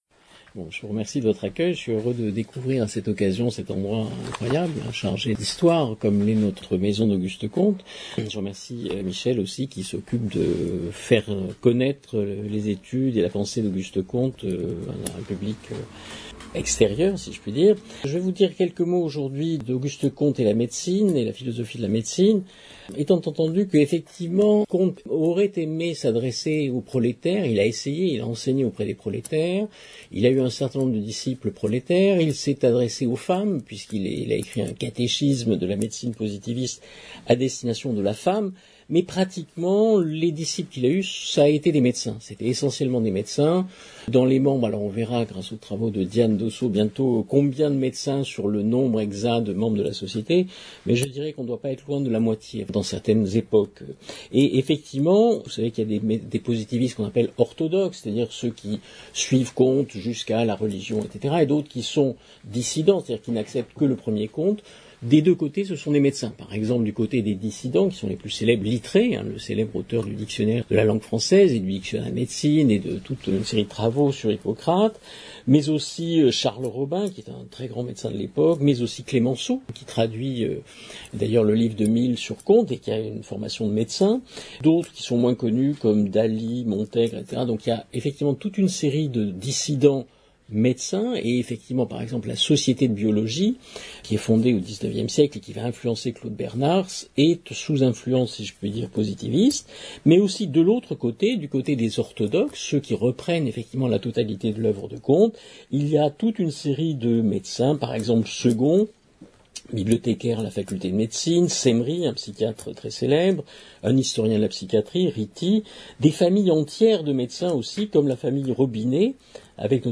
Pour écouter l’enregistrement de la soirée de lecture :